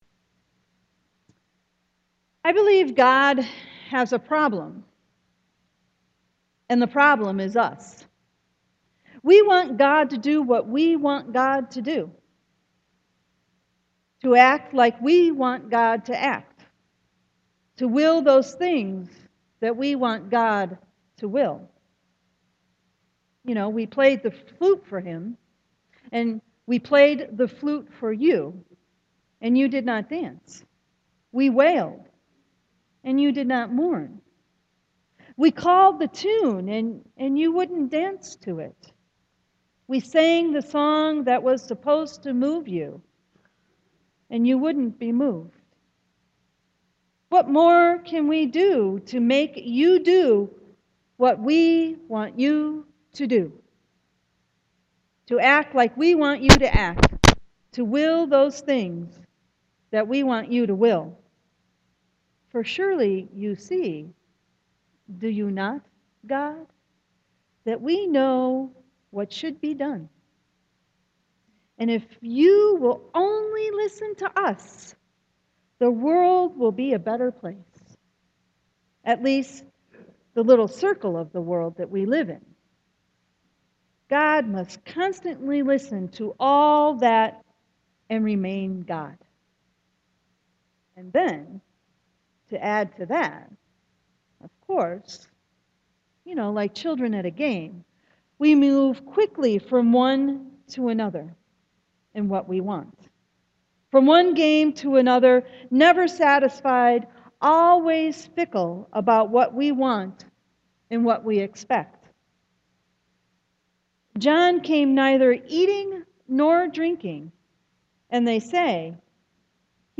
Sermon 7.6.2014